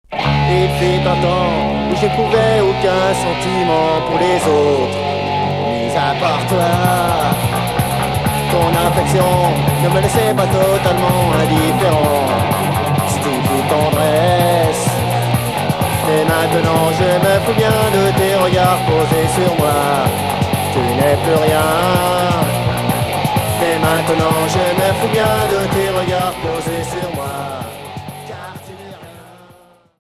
Punk industriel